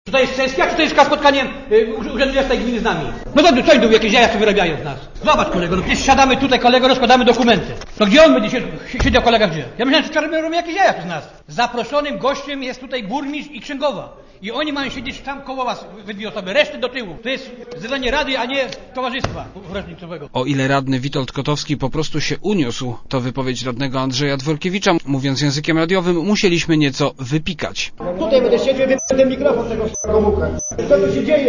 Krzyki, żale, wulgaryzmy, czyli radni przed sesją
thumb_sala_wschowa.jpgWschowa. Wyzwiska, wulgaryzmy, krzyki i pretensje – tak na kilka minut przed sesją wyglądała wczoraj sala obrad Rady Miejskiej Wschowy.
W rolach głównych wystąpili radni opozycji.
a właściwie krzyki miały miejsce na 5 minut przed sesją w czasie, gdy w sali była już część publiczności i radnych.